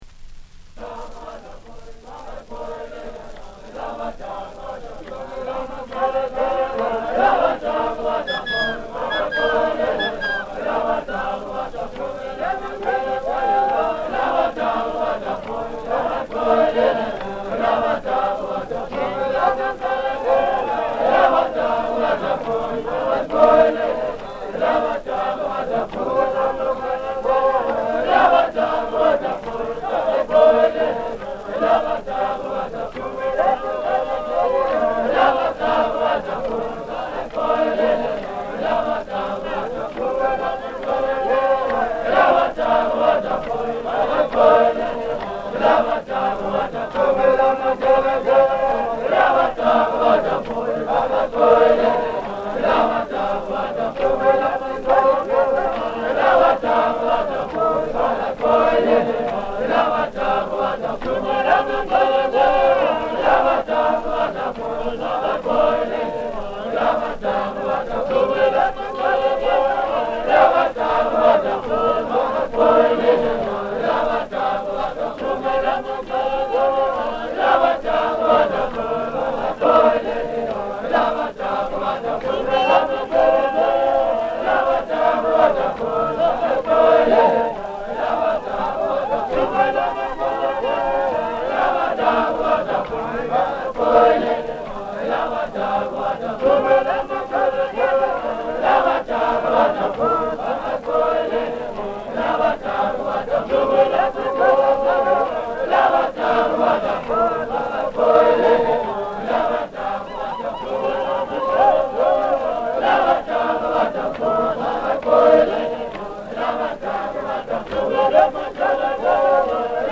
recorded 1930-06-29
78 rpm
(traditional)
CITY DEEP NATIVES - vocals
Though all recordings are credited to "City Deep Natives", there are at least two different performing groups over the four discs — a Shangaan vocal group on GR 17 and 18 and a Chopi timbila group on GR 15 and 16.
For example here on the B-side he notes that the track is "showing parallel movement of voices" and "medieval organum".